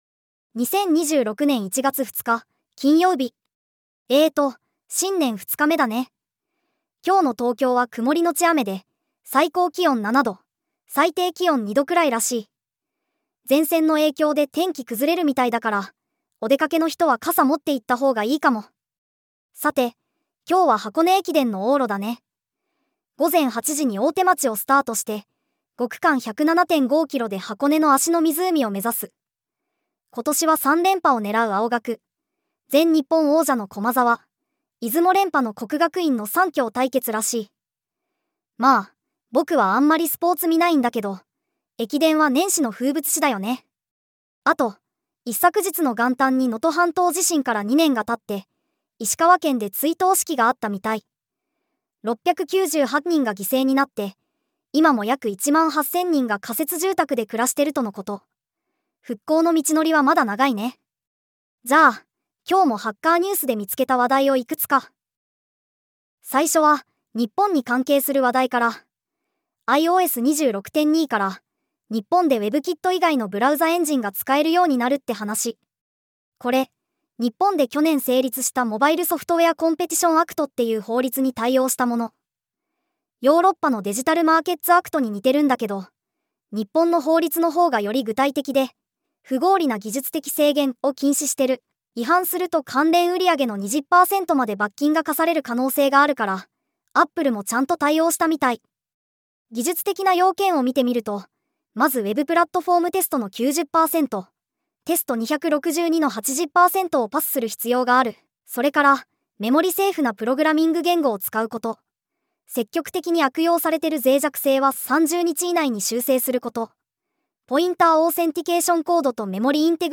テトさんに技術系ポッドキャストを読んでもらうだけ